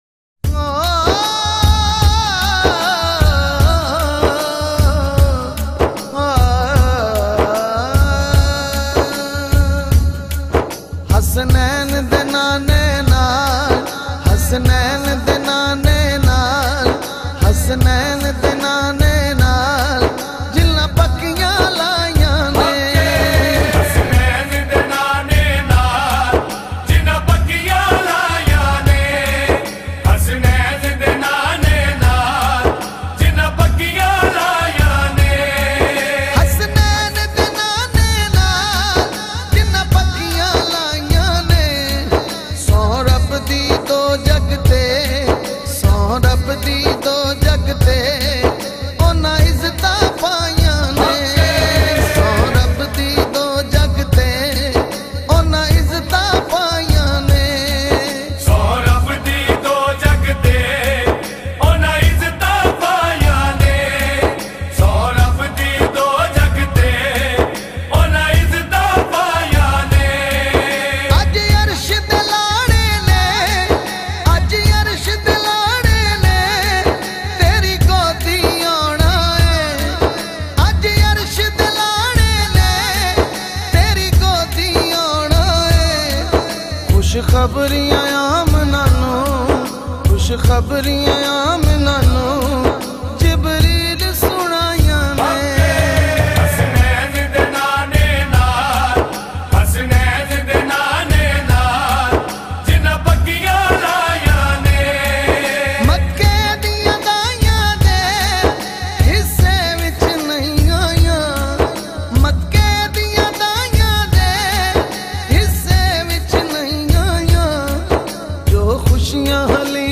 Naat in a Heart-Touching Voice
naat khawan
With a very pleasant voice and powerful performance